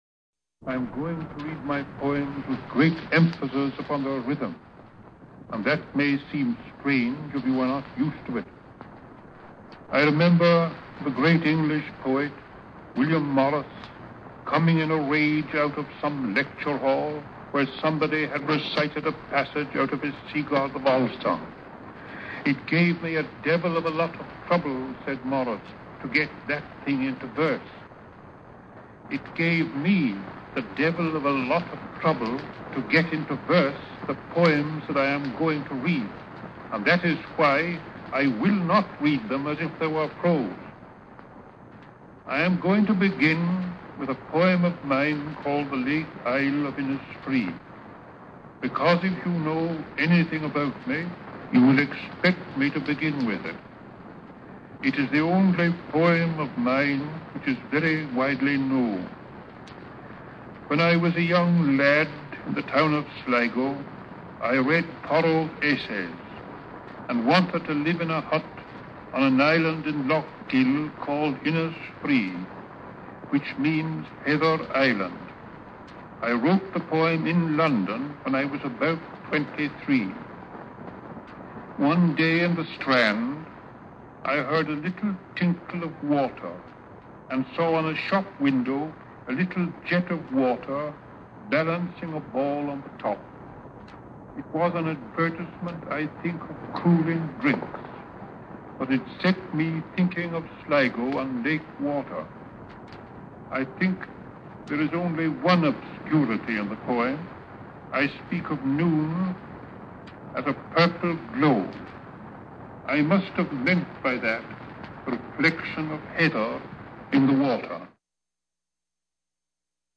Poetry Recitation
This is a three and a half minute BBC radio broadcast of William Butler Yeats's own voice in 1932 reciting two of his poems, one of which is the most famous of his early poetry, "The Lake Isle of Innisfree" (1888). Yeats prefaces this poetry recitation with an explanation of how he was inspired to write "The Lake Isle of Innisfree" and why he will try to recite it in a musical or performative way.